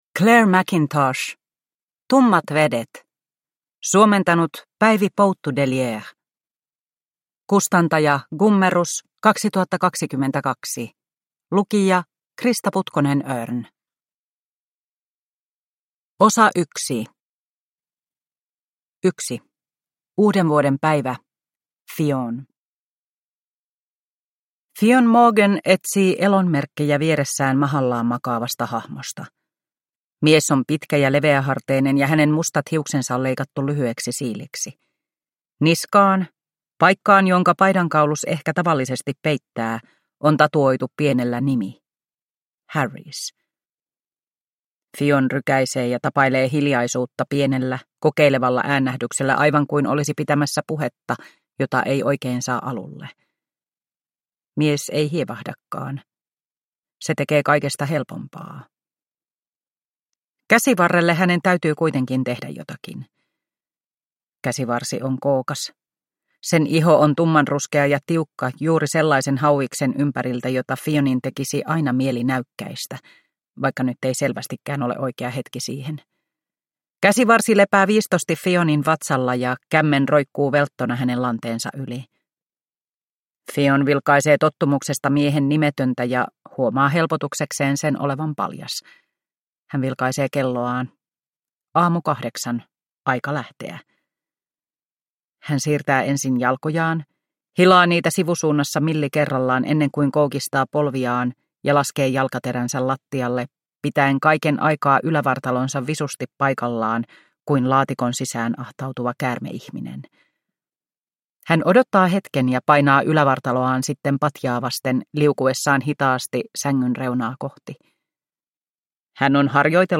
Tummat vedet – Ljudbok – Laddas ner